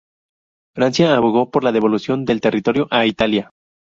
Pronunciado como (IPA)
/deboluˈθjon/